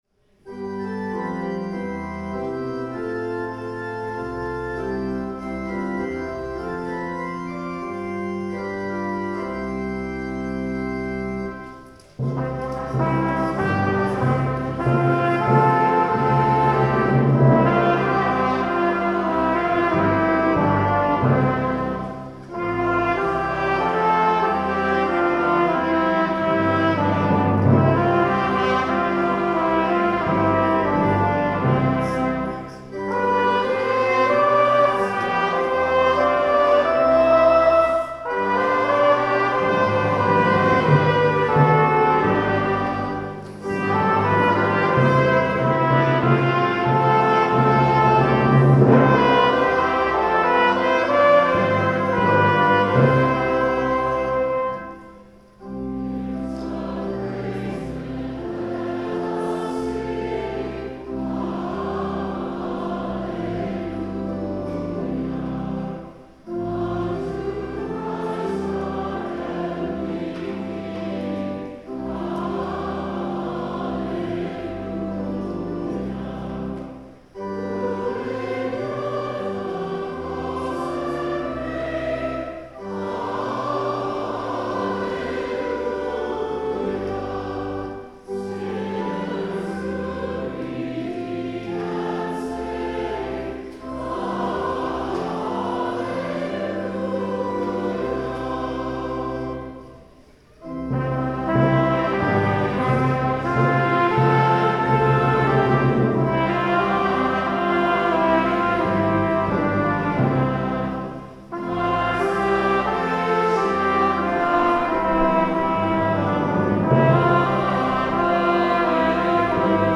EASTER SUNDAY Hymn 203: Jesus Christ is Risen Today Greeting, Land Acknowledgment & Collect of the Day First Reading: Isaiah 65:17-25 Psalm 118:1-2, 14-24: This is the day the Lord has made, let us rejoice and be glad.
Hymn 203
The Lord’s Prayer (sung)